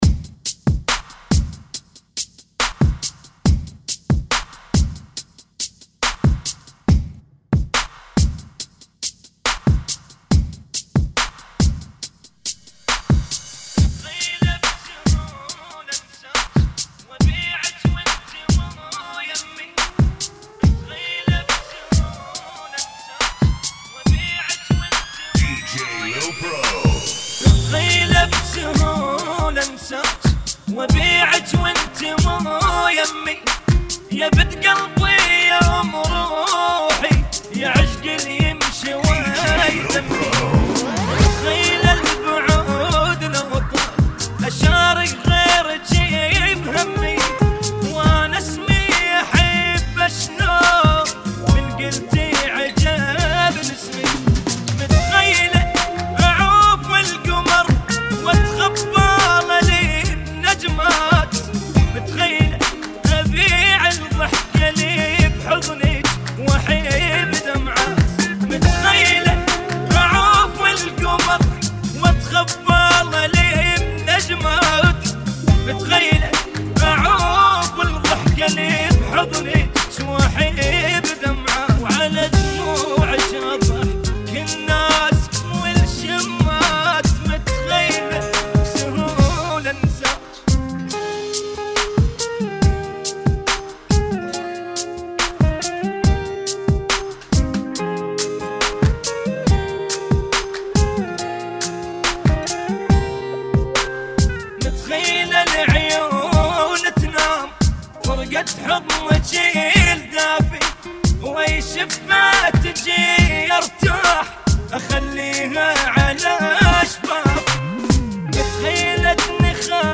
70 BPM